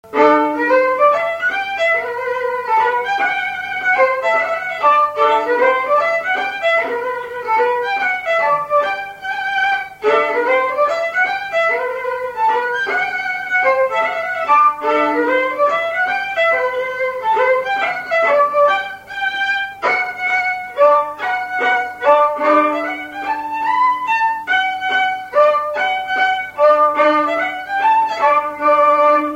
Mazure
Résumé instrumental Usage d'après l'analyste gestuel : danse